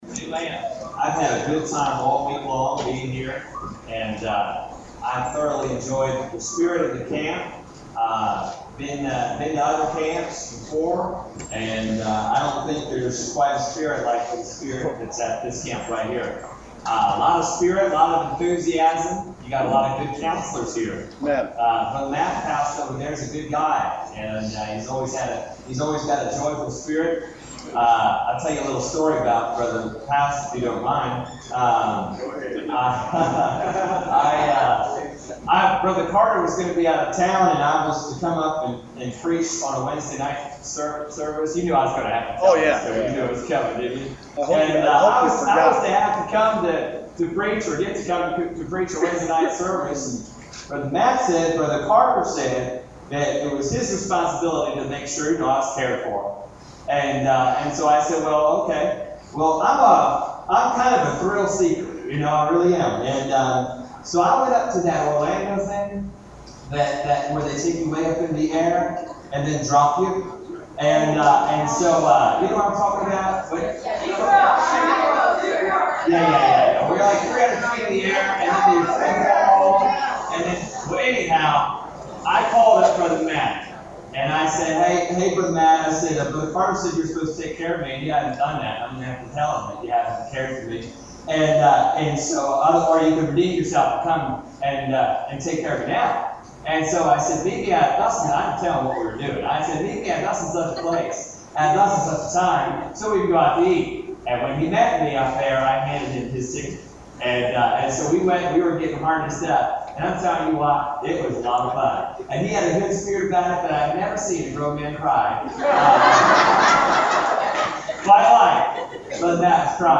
Service Type: Teen Camp